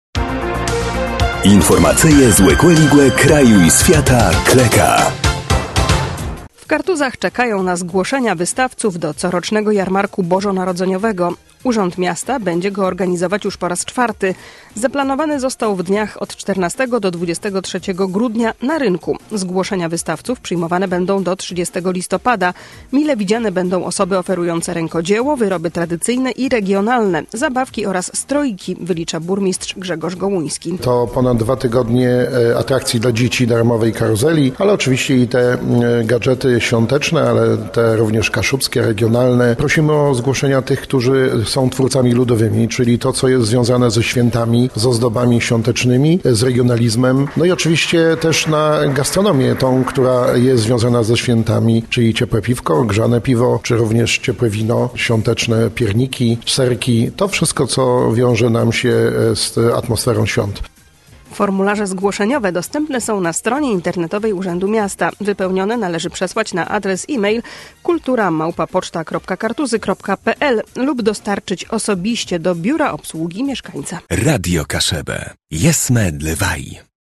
– Mile widziane będą osoby oferujące rękodzieło, wyroby tradycyjne i regionalne, zabawki oraz stroiki – wylicza burmistrz, Grzegorz Gołuński.